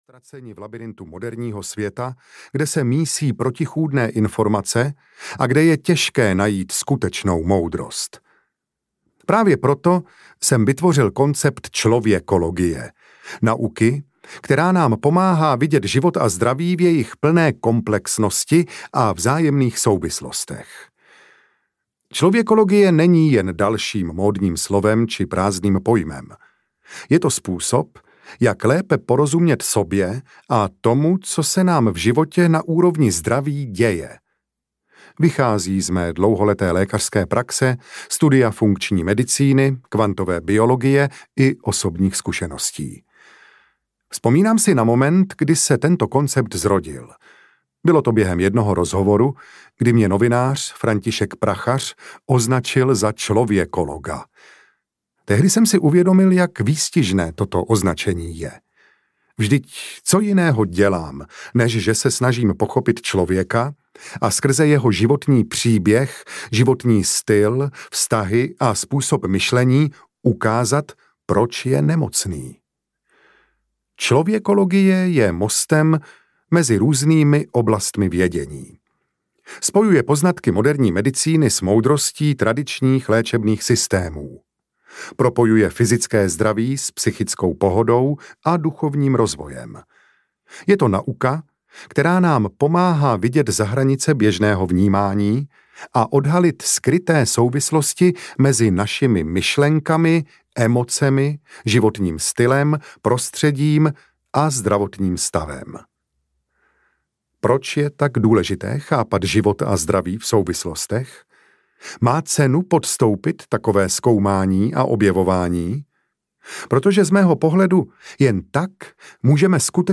Únava není normální audiokniha
Ukázka z knihy